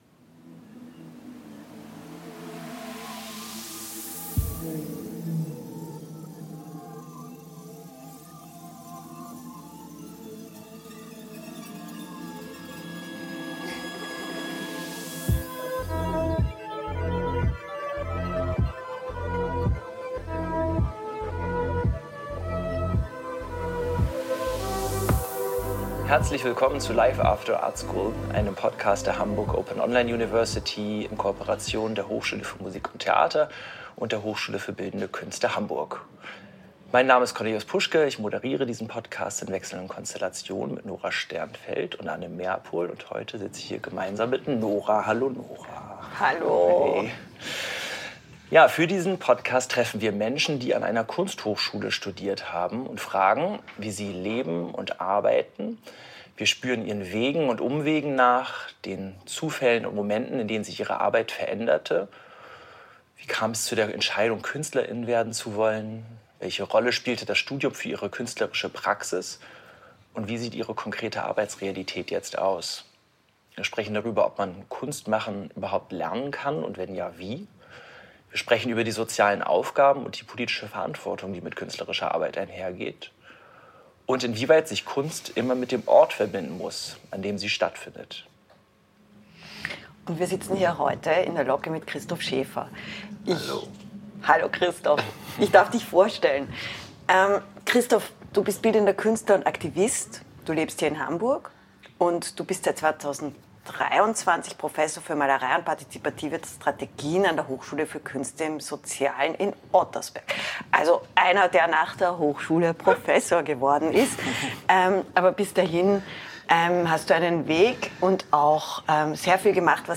in der Locke, unter dem Dach des Pudel Club